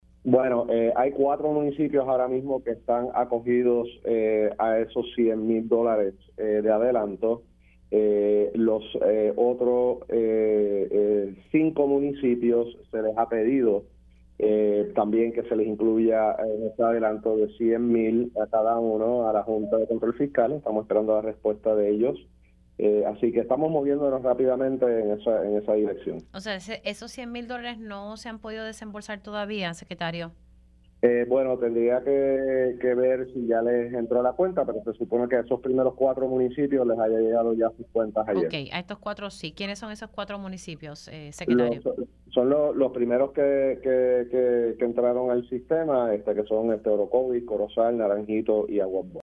El secretario de Seguridad Pública, General Arturo Garffer indicó en Pega’os en la Mañana que podría aumentar a unos 14 municipios con daños provocados por las lluvias torrenciales que han afectado a la isla desde el 19 de abril.